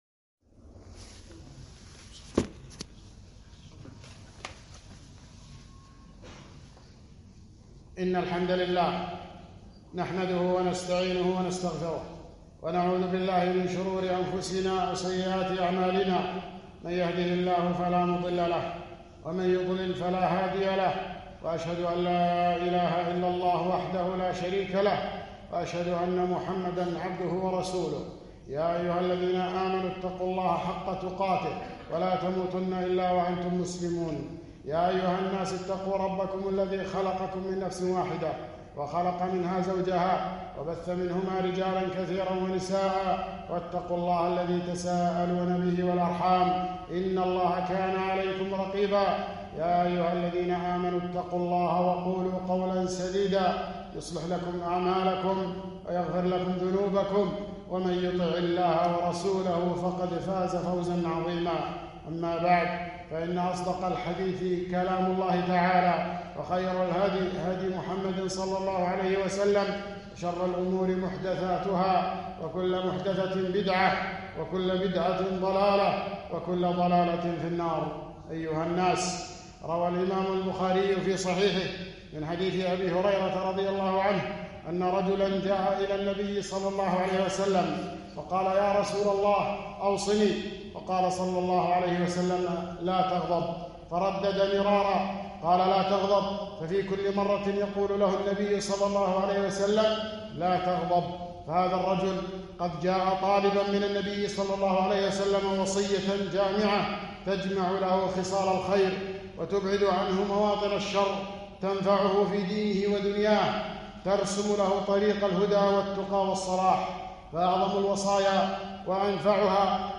خطبة - لا تغضب